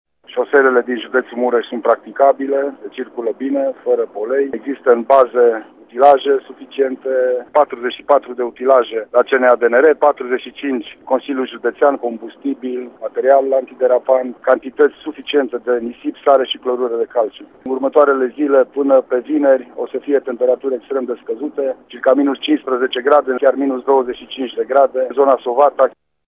Prefectul de Mureş, Lucian Goga, a asigurat că instituţiile responsabile sunt pregătite pentru a face faţă valului de zăpadă şi frig.